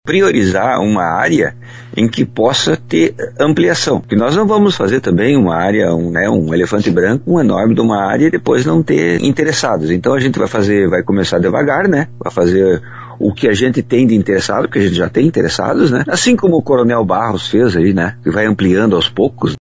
A prefeitura de Boa Vista do Cadeado avança no projeto de criar um distrito Industrial. Em entrevista para a RPI, o prefeito, João Paulo Beltrão dos Santos, disse que através de edital do município, um morador ofereceu uma área às margens da ERS 342, no acesso ao município. Segundo João Paulo, já há empresários interessados em terrenos para instalar ou expandir negócios e é preciso definir local para futuros desdobramentos.